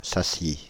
Sassy (French pronunciation: [sasi]
Fr-Paris--Sassy.ogg.mp3